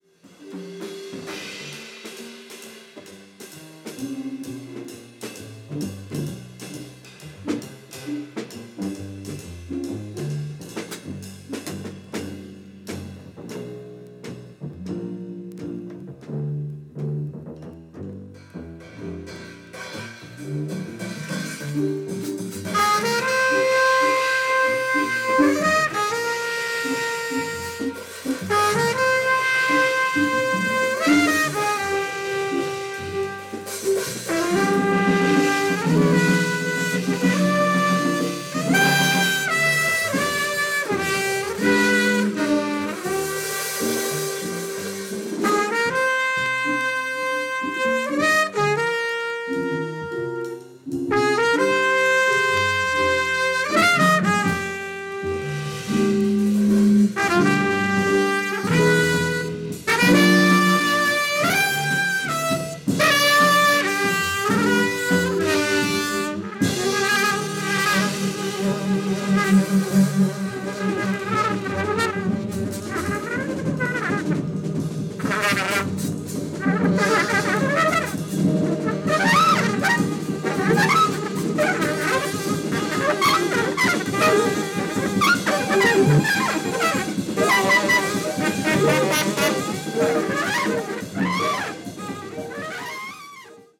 Alto Saxophone
Drums